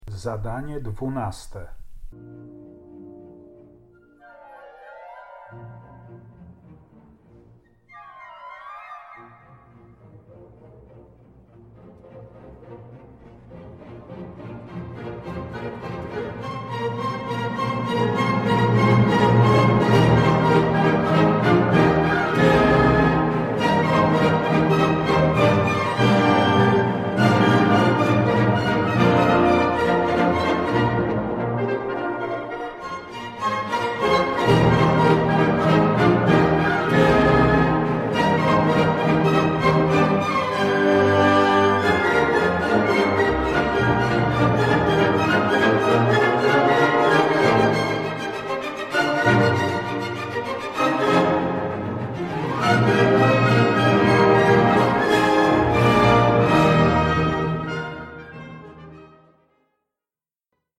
Fragment utworu symfonicznego
Zaprezentowany w nagraniu temat utworu – odwołujący się do tradycji rycerskich/szlacheckich – jest stylizacją polskiego tańca.